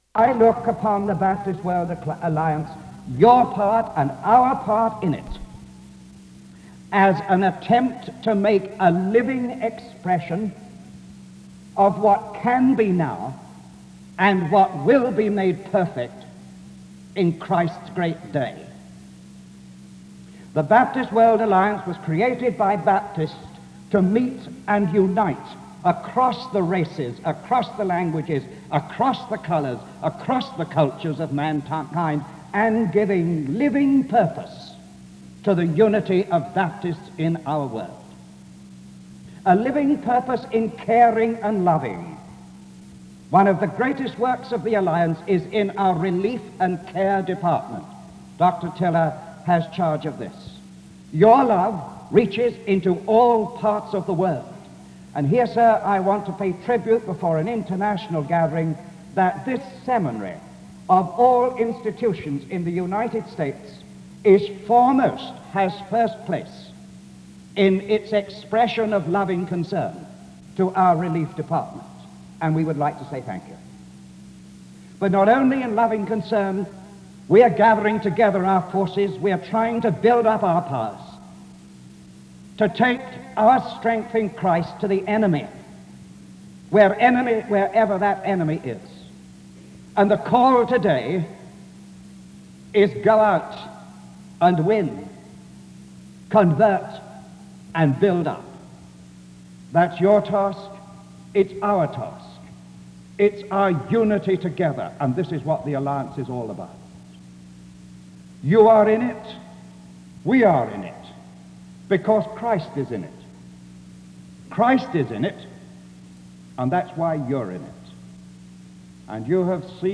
The service begins with a prayer from 0:00-1:01. An introduction to the Executive Committee of the Baptist World Alliance is given from 1:08-3:24. The Executive Committee members give reports and share their mission from 3:25-19:29.